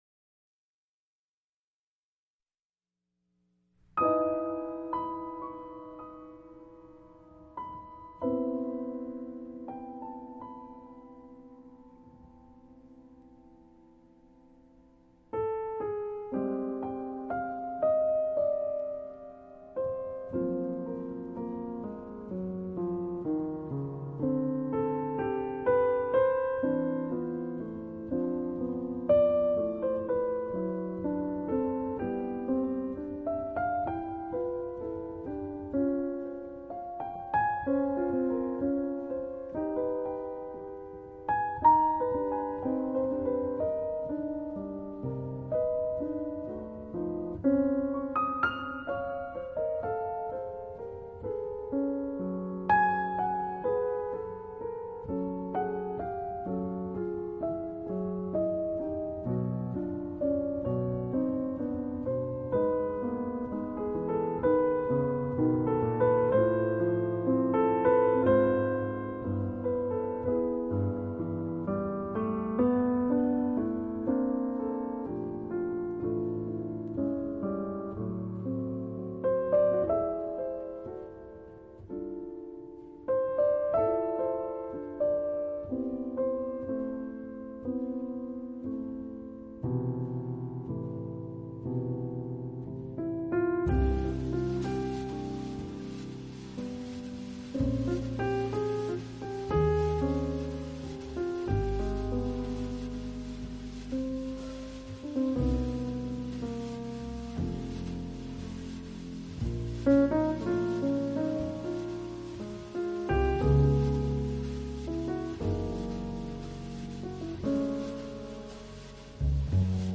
【爵士钢琴】
但他懂得掌握钢琴浪漫与甜美的特质，而且将这些特质发挥得恰到好处，我猜想满